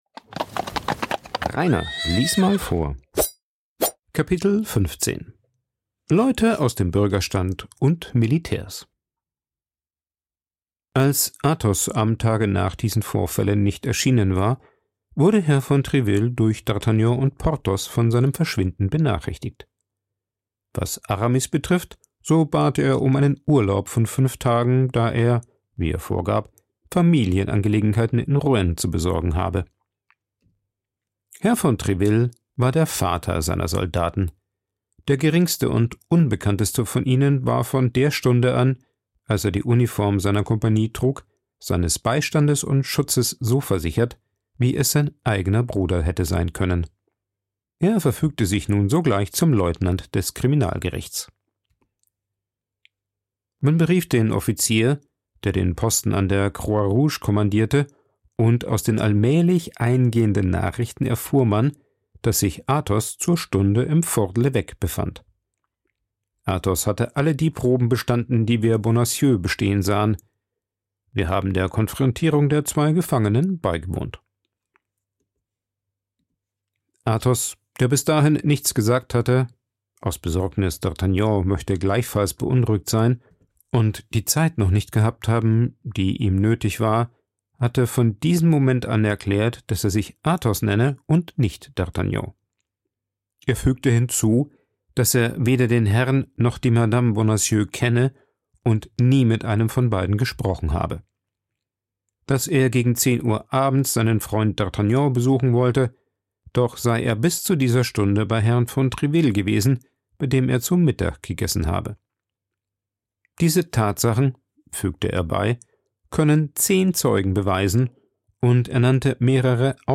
Dort ist bereits der Kardinal und es kommt zu einem kleinen Machtkampf zwischen den beiden. Am Ende unterschreibt der König die Entlassung von Athos, allerdings ergibt sich bei Tréville das Gefühl, dass der Kardinal noch etwas im Schilde führt. Vorgelesen
aufgenommen und bearbeitet im Coworking Space Rayaworx, Santanyí, Mallorca.